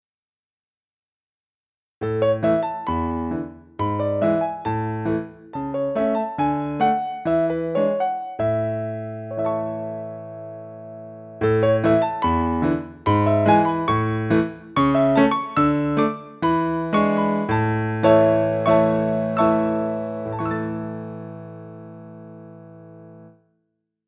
足取り軽く、バ レエのレッスン曲のような